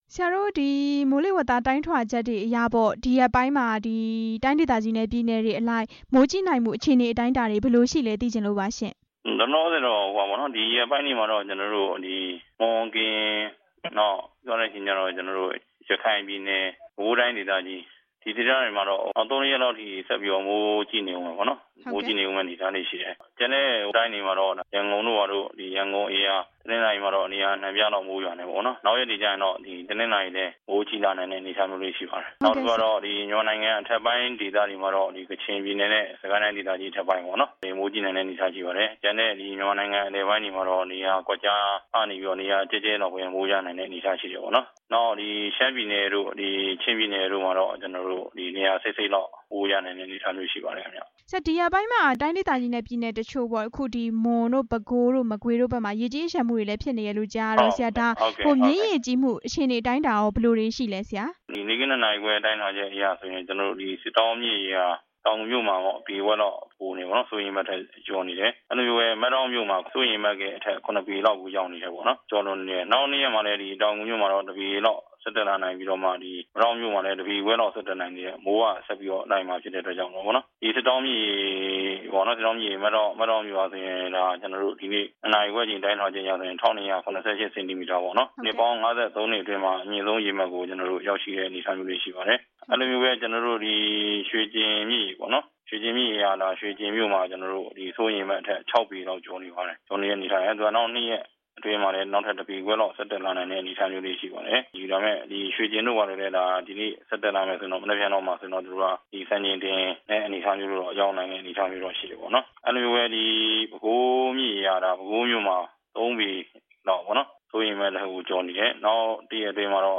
မွန်၊ ကရင်၊ ပဲခူး၊ ရခိုင် စတဲ့ တိုင်းနဲ့ပြည်နယ် ၄ ခုမှာ နောက် ၃ ရက်အထိ မိုးကြီးနိုင်တယ်လို့ မိုးလေဝသနဲ့ ဇလဗေဒ ညွှန်ကြားမှုဦးစီးဌာန ဒုတိယညွှန်ကြားရေးမှုးချုပ် ဦးကျော်မိုးဦးက ပြောပါတယ်။